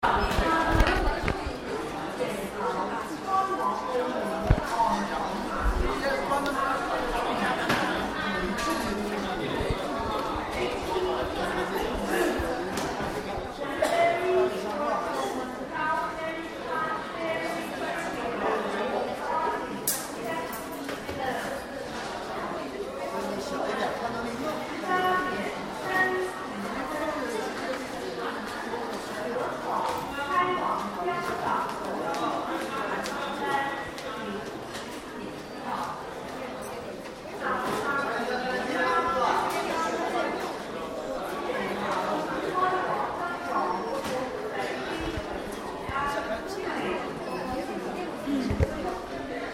Ambiance de rues à Shenzen 2
Ambiance de rues à Shenzen: klaxons, voix des passants